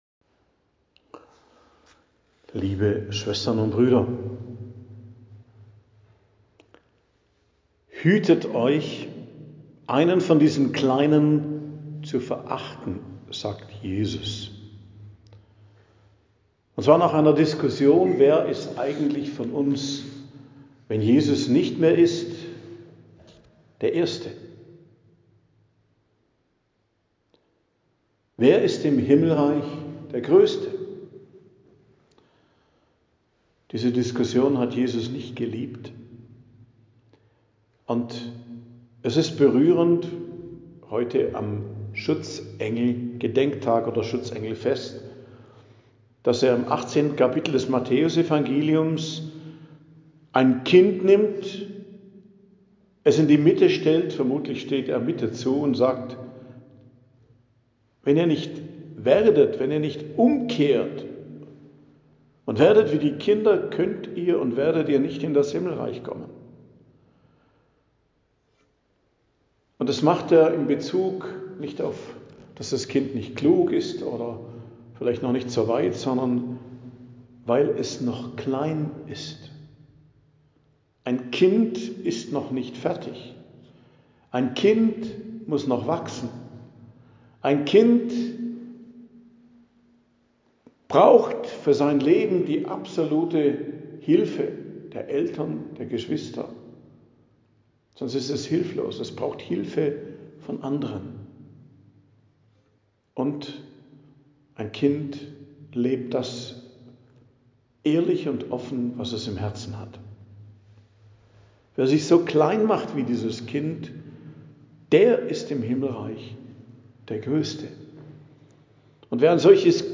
Predigt am Gedenktag Heilige Schutzengel, 2.10.2025